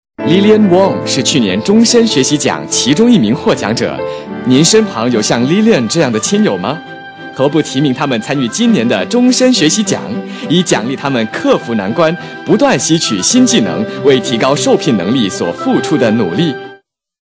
Sprecher chinesisch / mandarin für Werbung, internet, podcast, e-learning, Dokumentationen uva.
Kein Dialekt
chinese voice over artist